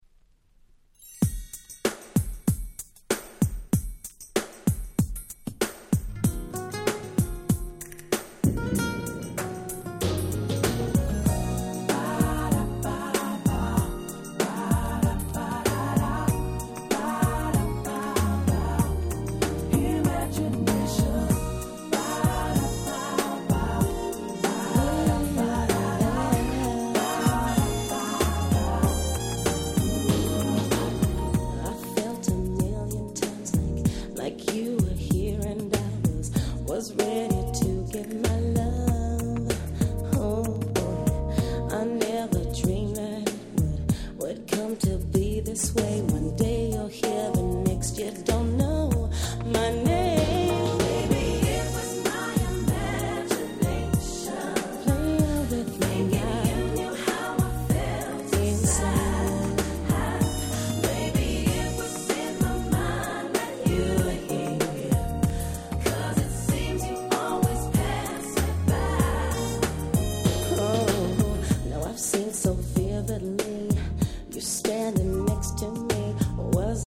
96' Nice R&B LP !!